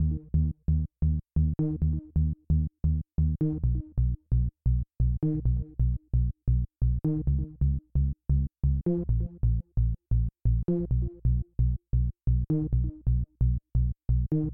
描述：一个波浪形的低音线回路，有一个更重的低音。
Tag: 132 bpm Trance Loops Bass Synth Loops 2.45 MB wav Key : Unknown